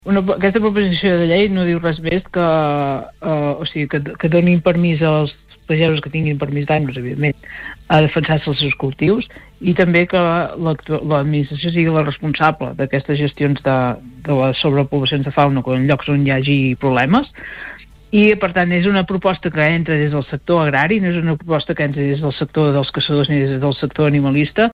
En una entrevista al Supermatí